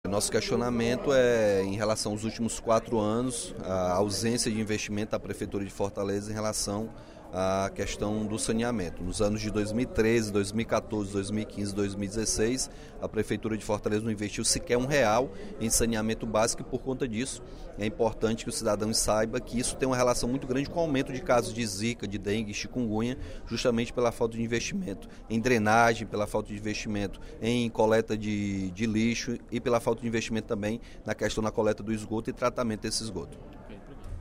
O deputado Capitão Wagner (PR) criticou, no primeiro expediente da sessão plenária desta terça-feira (21/02), a falta de investimentos em saneamento em Fortaleza.
Dep. Capitão Wagner (PR) Agência de Notícias da ALCE